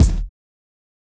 Kicks
BattleCatBigKick.wav